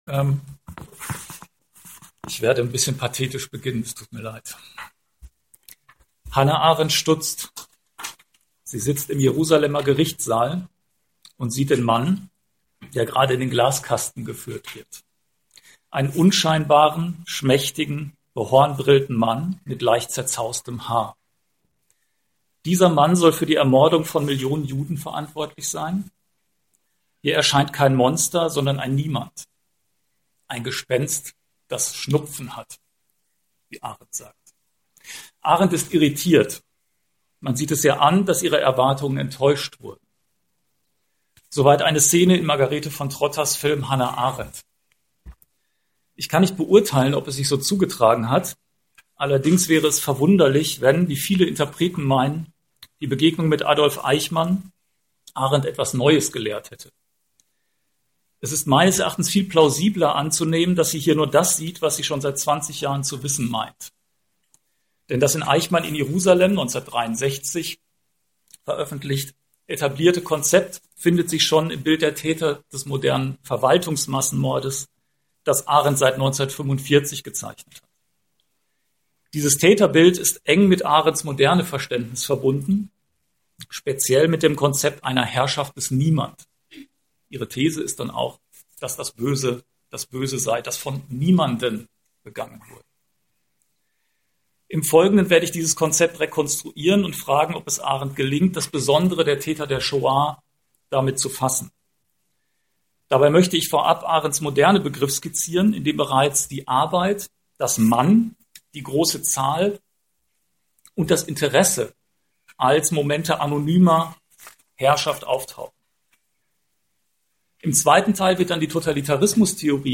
Vortrag vom 16.6.2015 an der Uni Essen